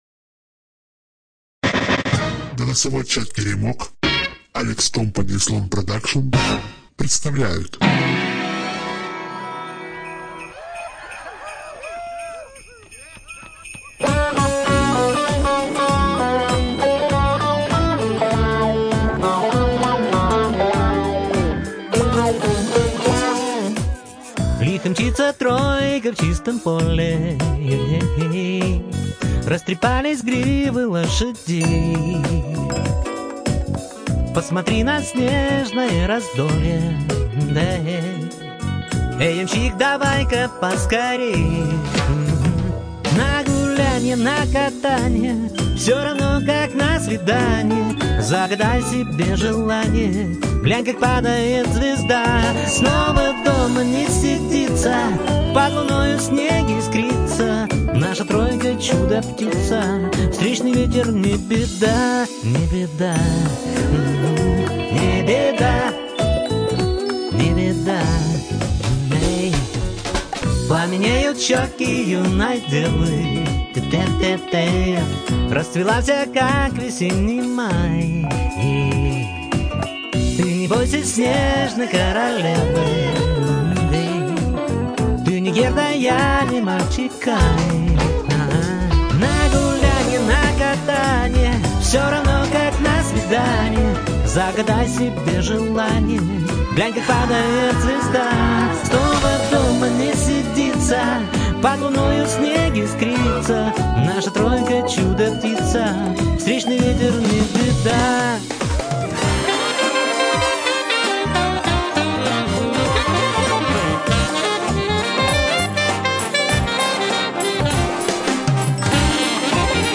Студия звукозаписиТеремок 4